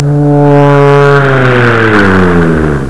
snd_13353_vroom.wav